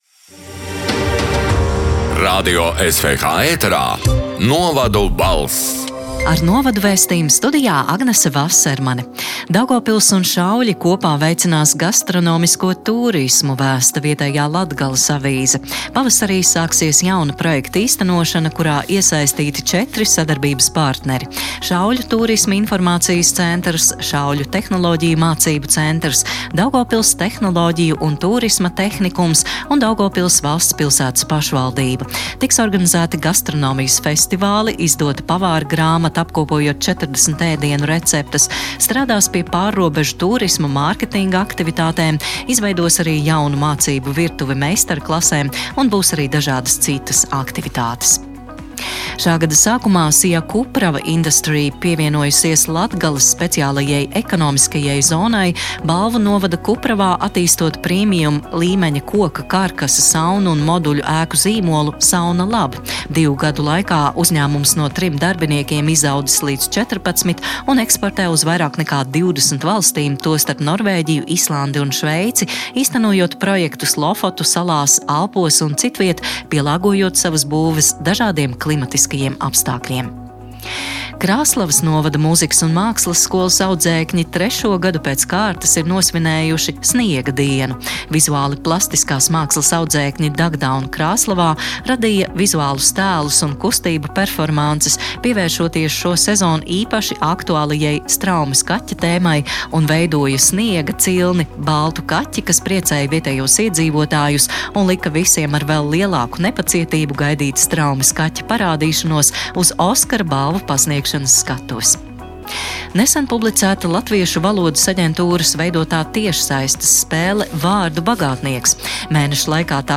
“Novadu balss” 7. marta ziņu raidījuma ieraksts: